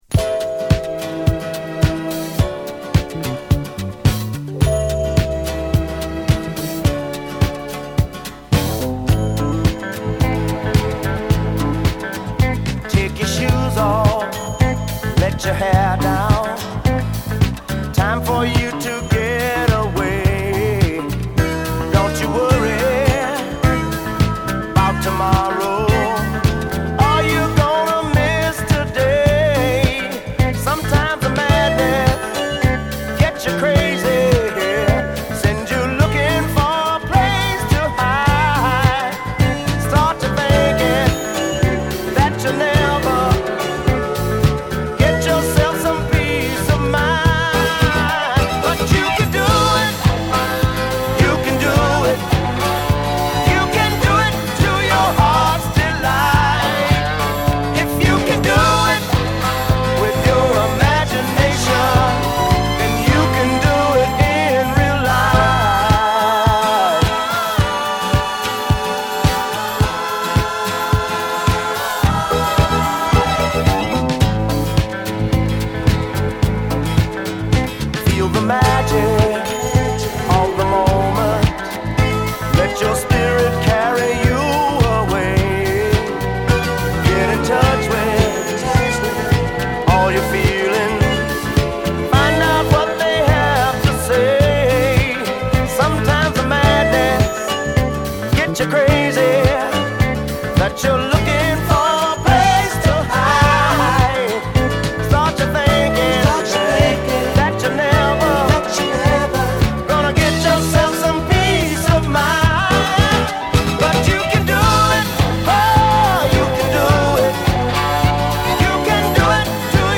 ダンサブルなメロウソウル